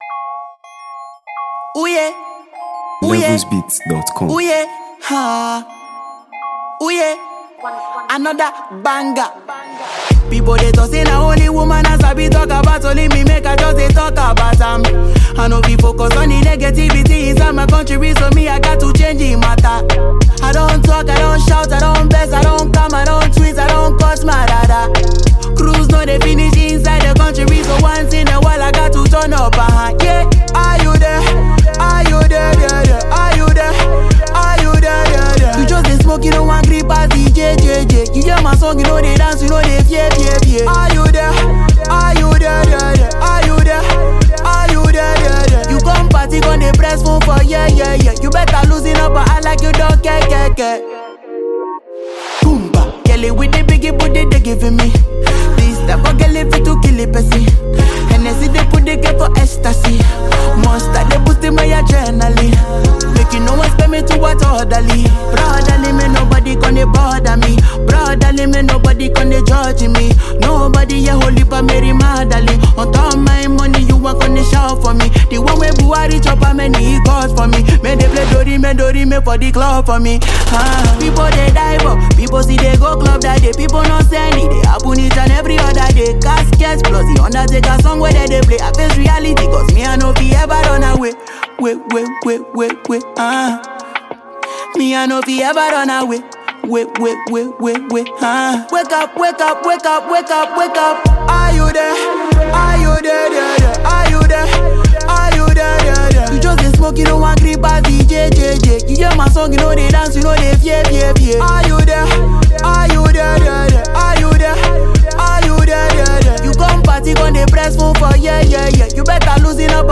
Nigeria Music 2025 3:13